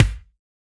BEAT KICK 04.WAV